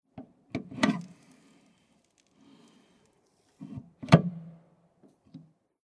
Innere Tür öffnen und schliessen
14596_Innere_Tuer_oeffnen_und_schliessen.mp3